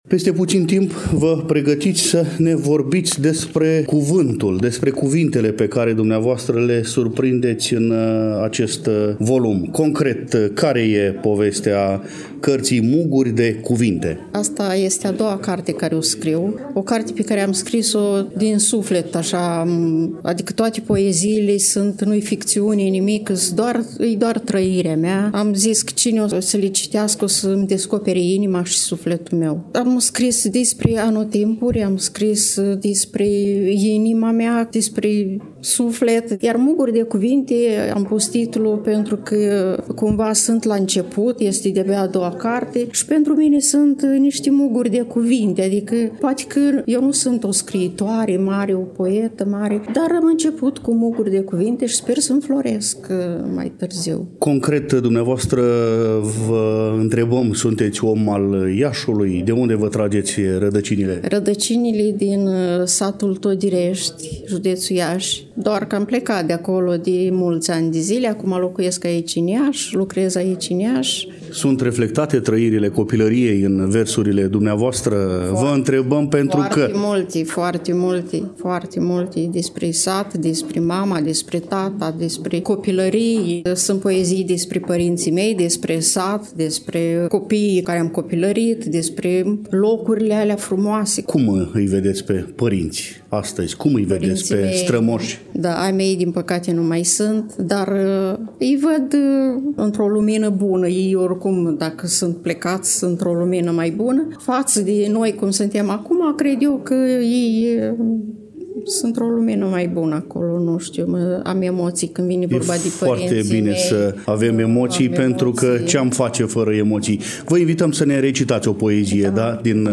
Stimați prieteni, în următoarele minute ralatăm de la o altă manifestare culturală organizată de către reprezentanții Societății Scriitorilor Fără Frontiere în parteneriat cu cei ai Muzeului „Mihail Kogălniceanu” din cadrul Complexului Muzeal Național Moldova.